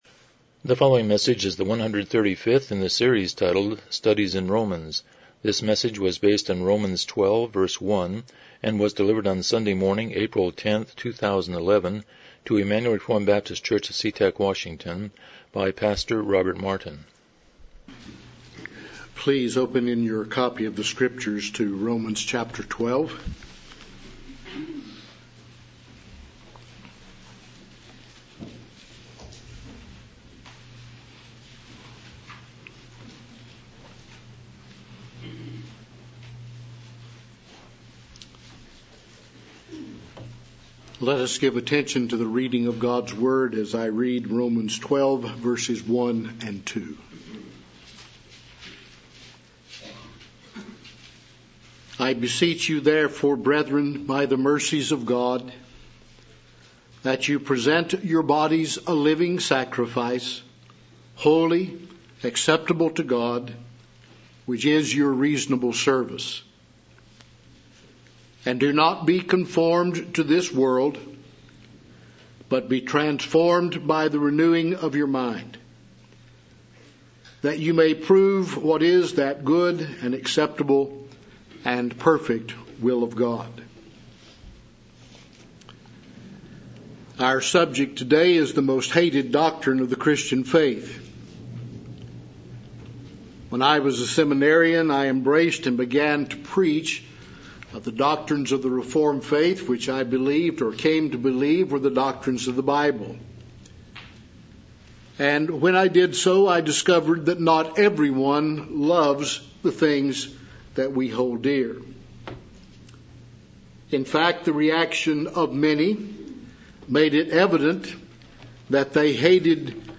Romans 12:1 Service Type: Morning Worship « 125 Chapter 25:3-4 Marriage